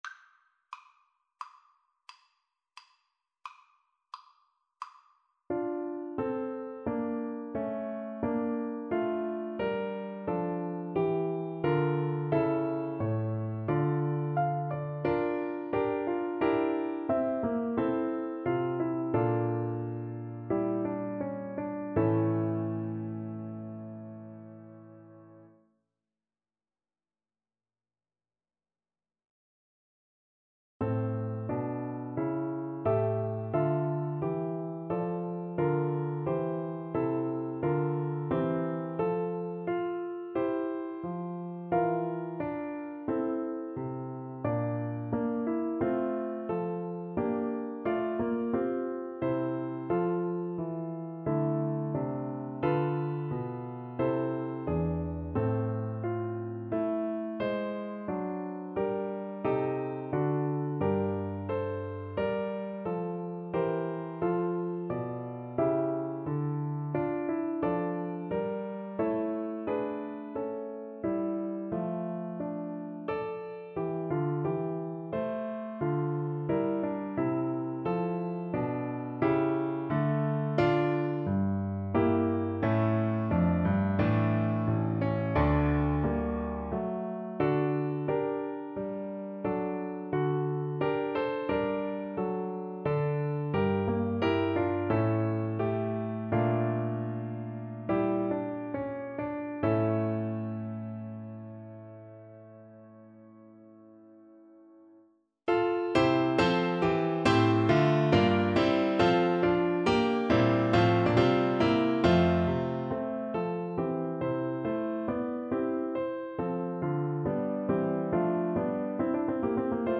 Adagio =88
Classical (View more Classical Violin Music)